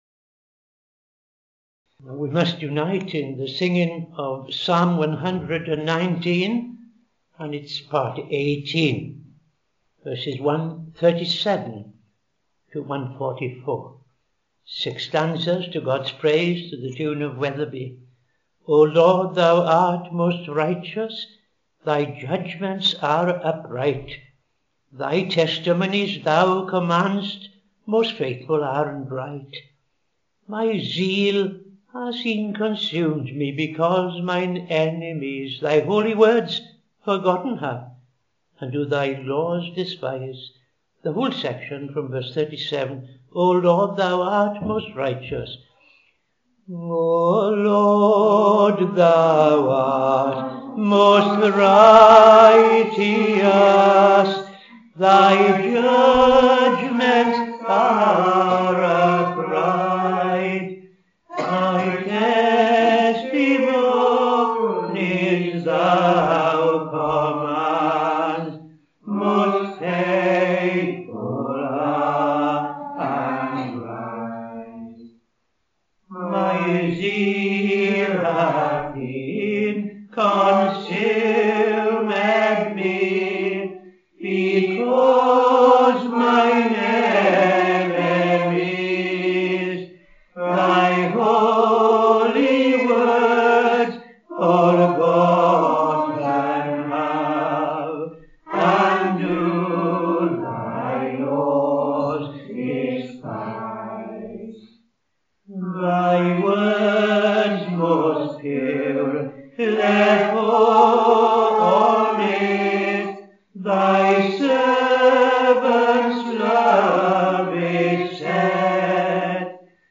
Evening Service - TFCChurch
5.00 pm Evening Service Opening Prayer and O.T. Reading I Chronicles 6:1-15